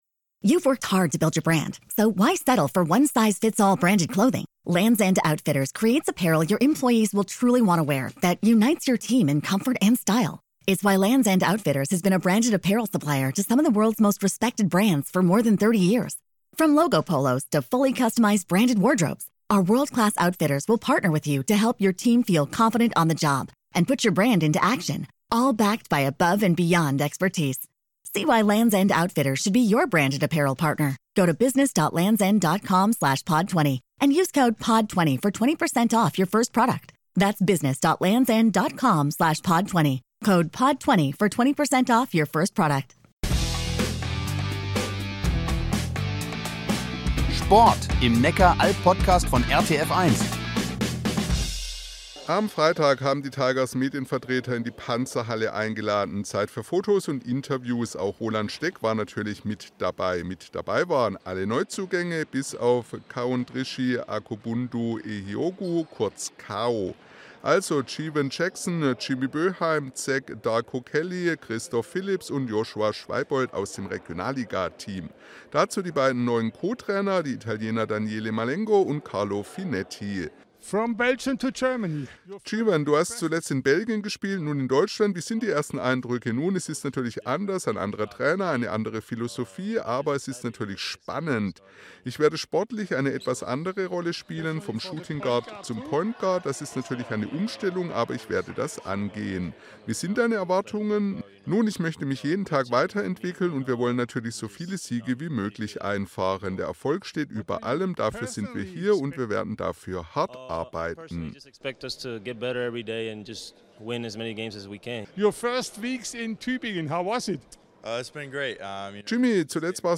Tigers Tübingen: Interviews mit den Neuzugängen ~ RTF1 Neckar-Alb Podcast | Reutlingen Tübingen Zollernalb Podcast
tigers-tuebingen-neuzugaenge-interview.mp3